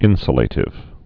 (ĭnsə-lātĭv, ĭnsyə-)